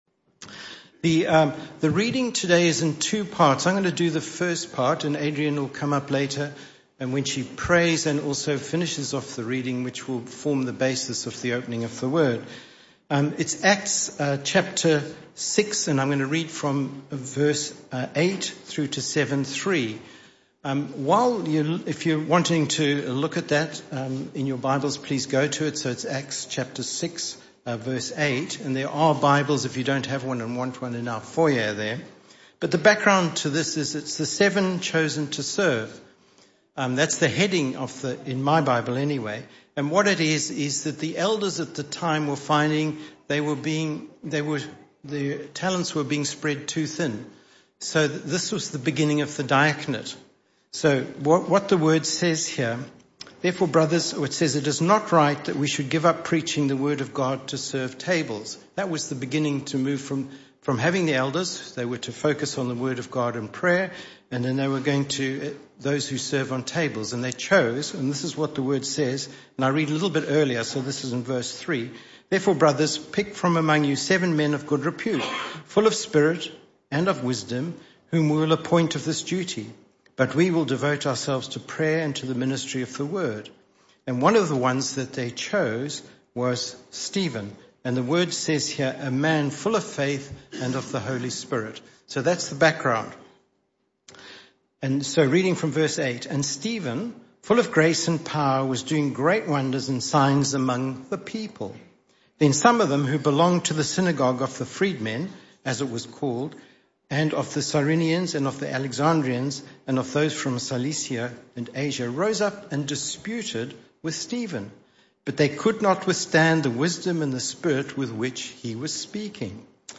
This talk was a one-off talk in the AM Service.